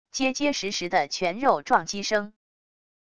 结结实实的拳肉撞击声wav音频